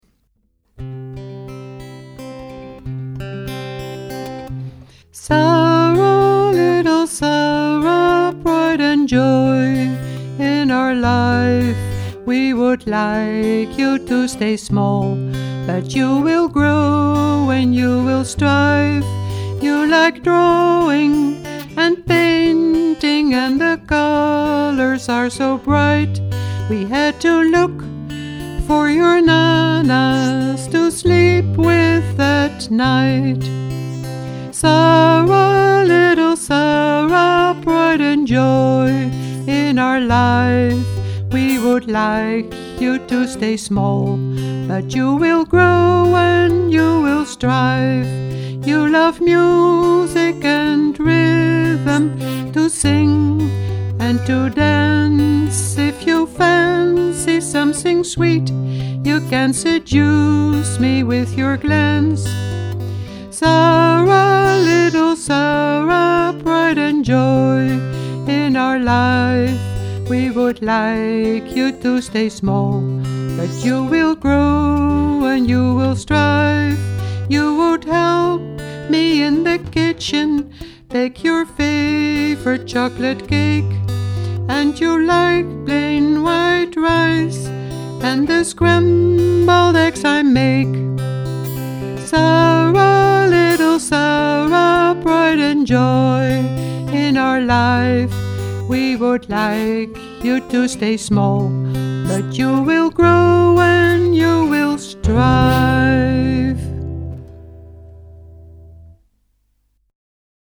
Since then they perform as a trio.